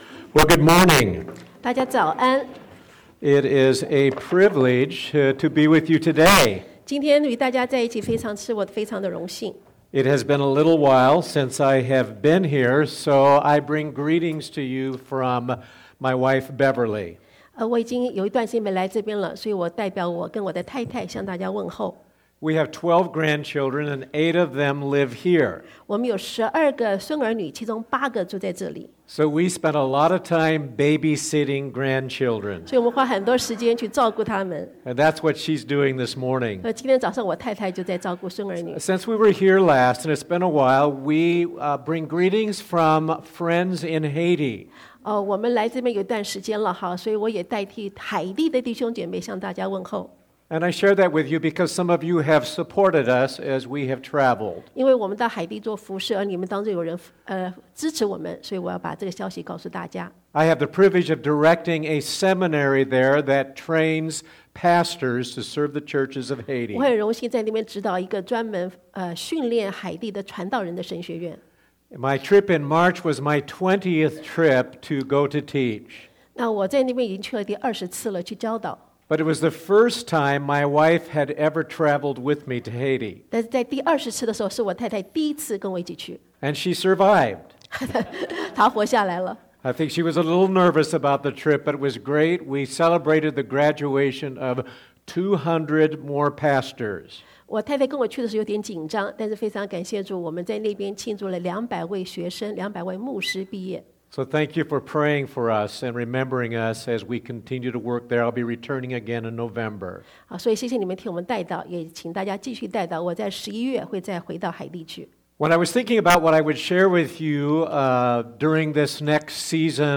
Passage: Ecclesiastes 1 & 2 Service Type: Sunday AM